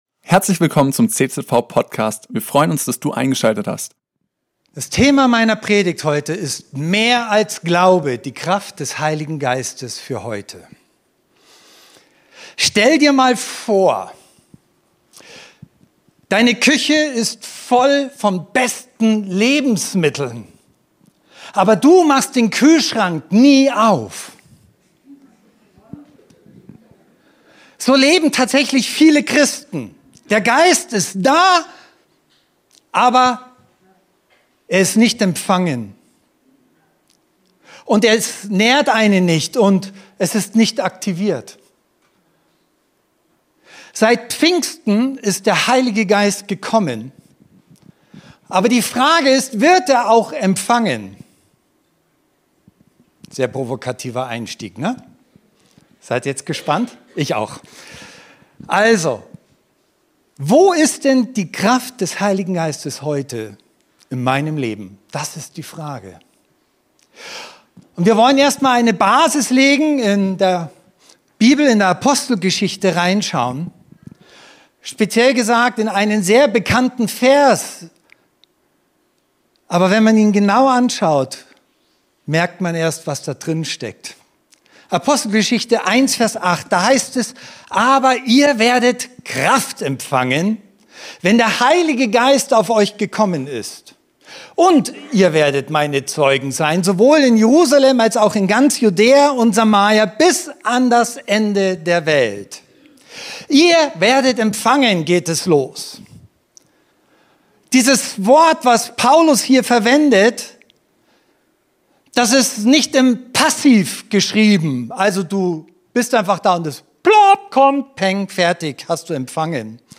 Predigt zu Pfingsten mit dem Titel: Mehr als Glaube - Die Kraft des Heiligen Geistes für heute